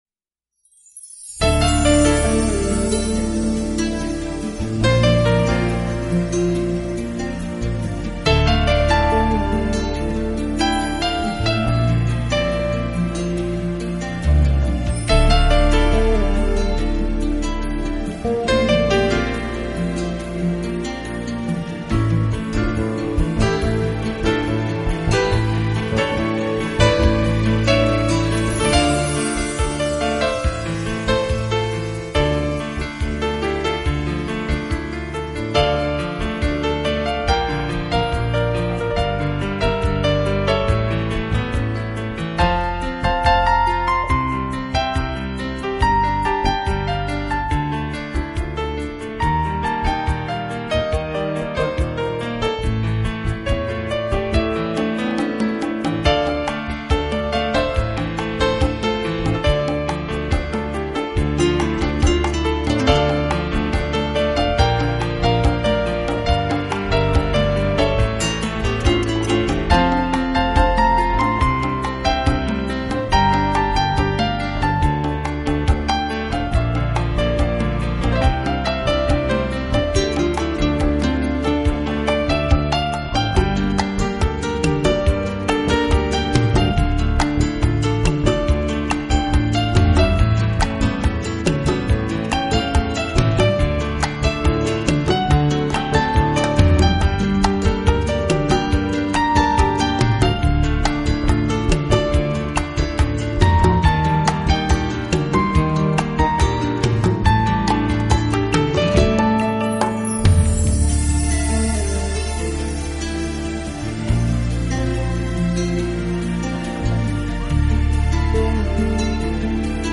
【新世纪纯音乐】
弱一点，但更显轻快平和，象天边的那一抹微云，悠远而自在。